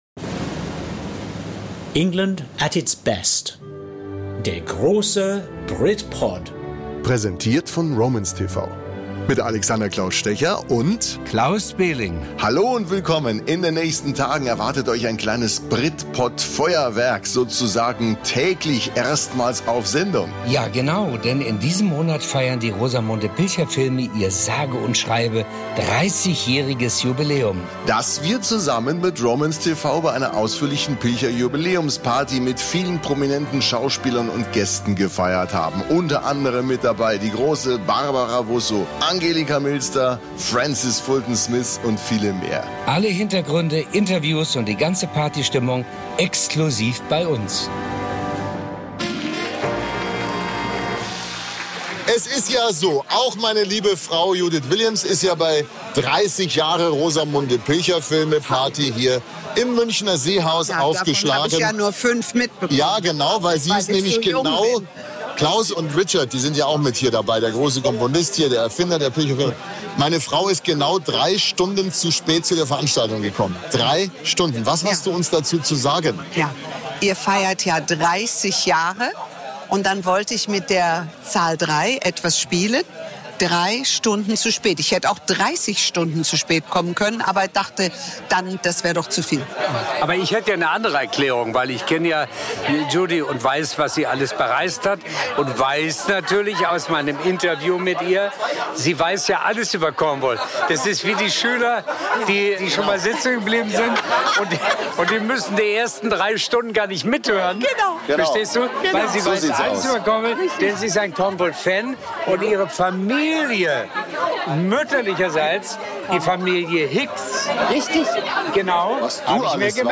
beliebten Reihe ins Seehaus im Englischen Garten in München